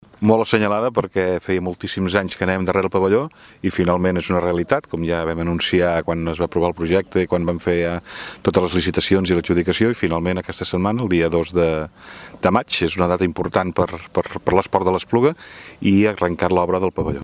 ÀUDIO: El regidor de serveis municipals, Joan Amigó, celebra l’arrancada de les obres